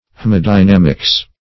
Meaning of haemadynamics. haemadynamics synonyms, pronunciation, spelling and more from Free Dictionary.
Search Result for " haemadynamics" : The Collaborative International Dictionary of English v.0.48: Haemadynamics \H[ae]ma*dy*nam"ics\ (h[=e]`m[.a]*d[-i]*n[a^]m"[i^]ks or h[e^]m`[.a]*d[i^]-, n. Same as Hemadynamics .